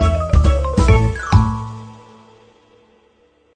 背包-游戏失败.mp3